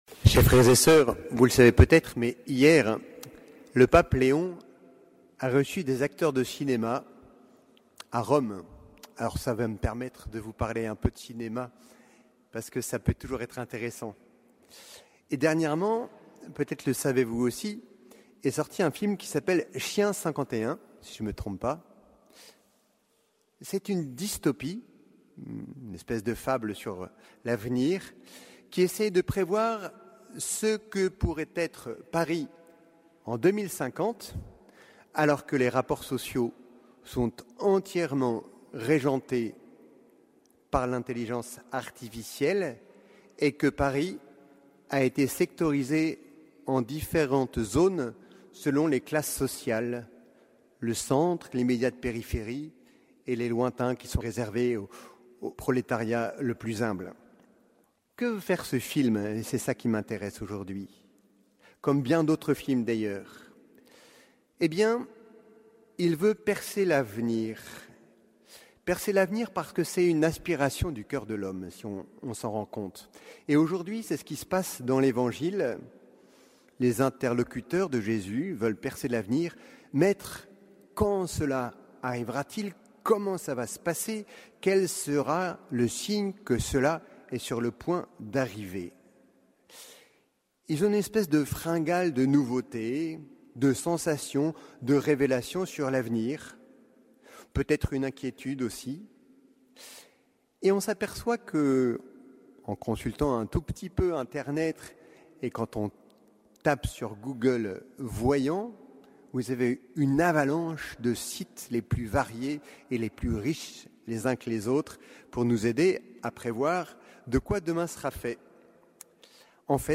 Homélie du 33e dimanche du Temps Ordinaire